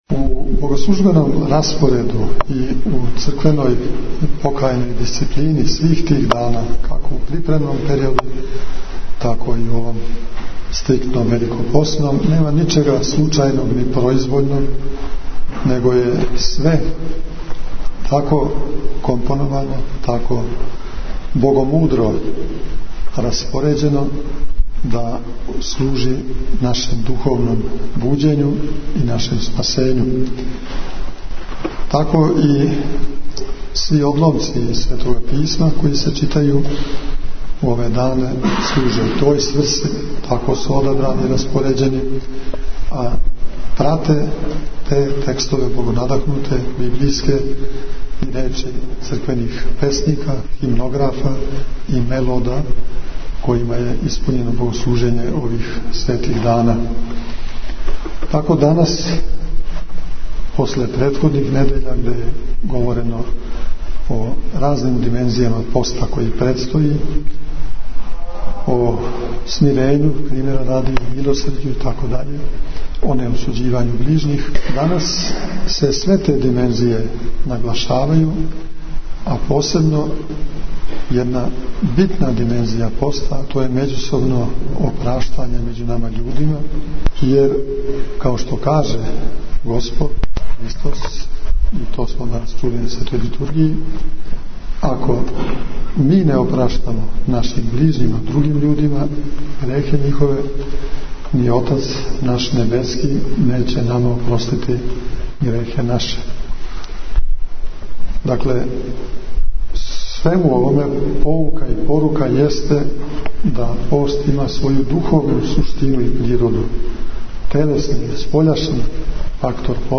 Беседа Његовог Преосвештенства Епископа бачког Господина др Иринеја изговорена на вечерњој служби, у Недељу Сиропусну, 14. фебруара 2010. године, у новосадском Саборном храму.